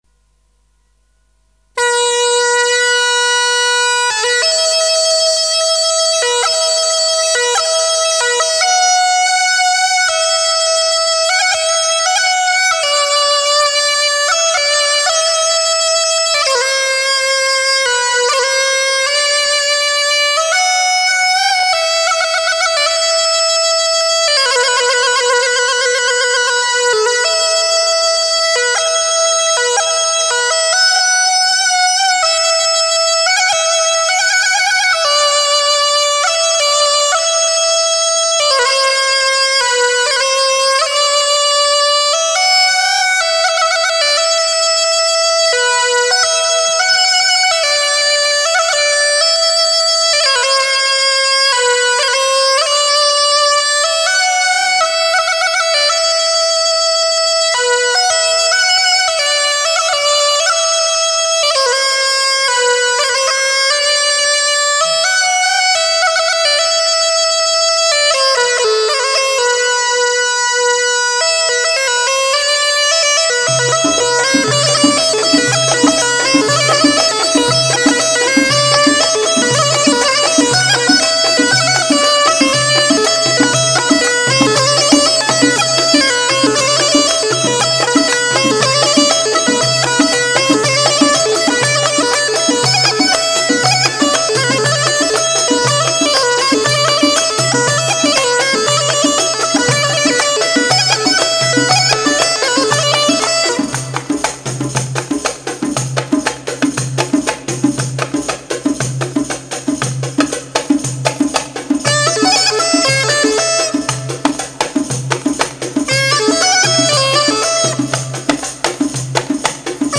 Bikalaam